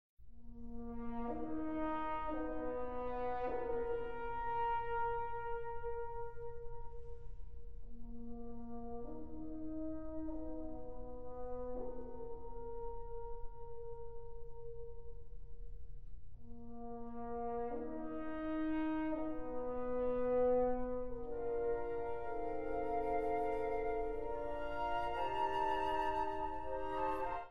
Yr 7 brass listening track 3